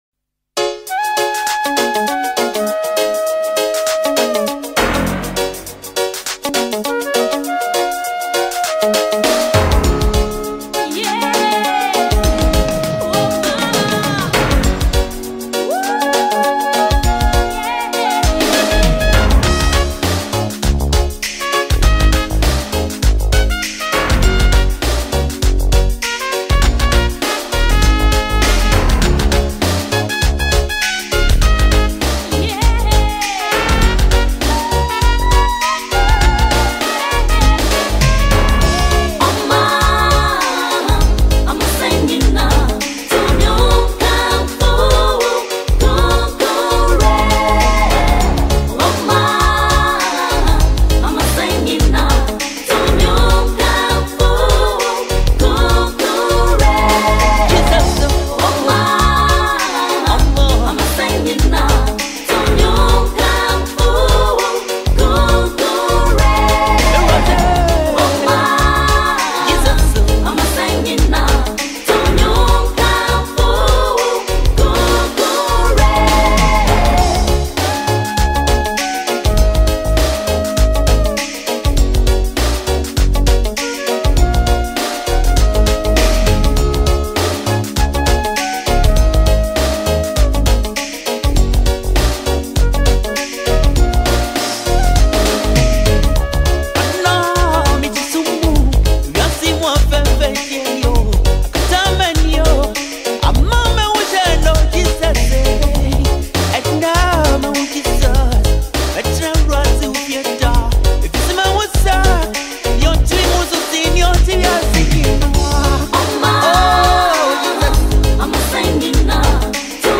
Ghanaian highlife